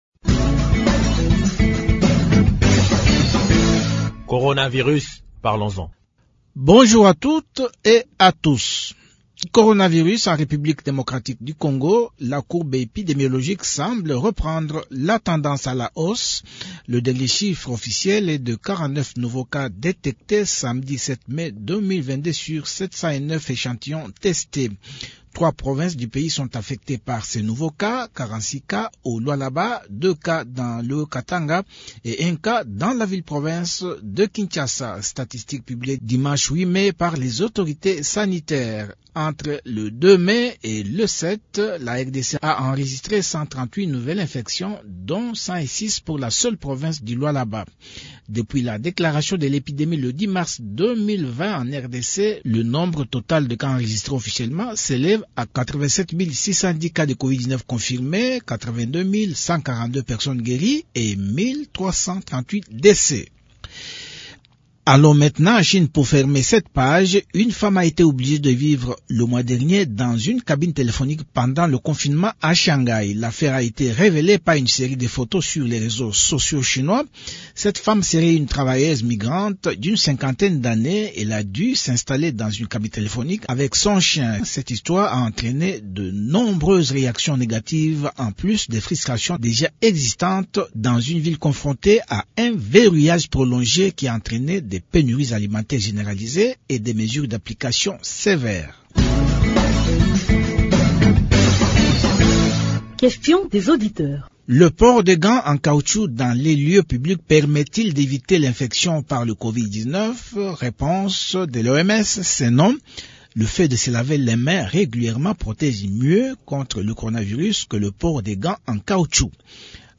Le ministre provincial de la santé du Kasaï Oriental, le Dr. Célestin Kadima Lufuluabo explique pourquoi la campagne de masse contre le Covid-19 a été un succès dans cette province.